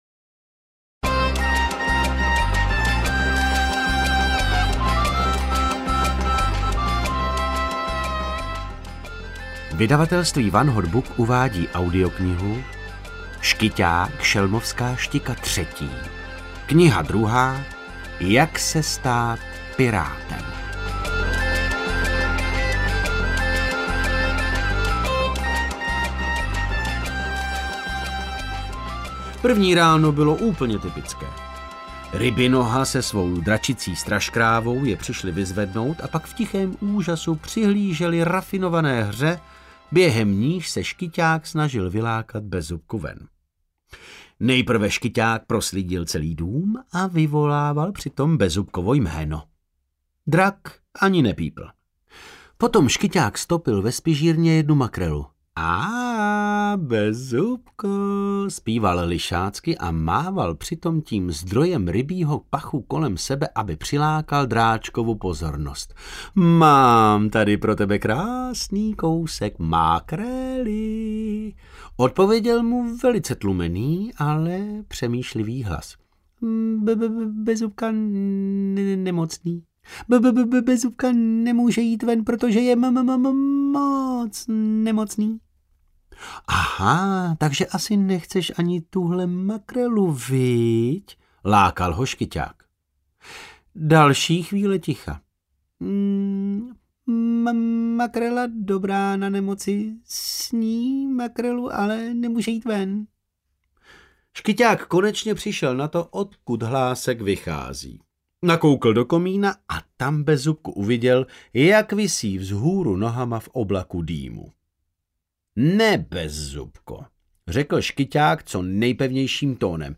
Ukázka z knihy
• InterpretDavid Novotný
jak-se-stat-piratem-audiokniha